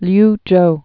(lyjō) also Liu·chow (-chō)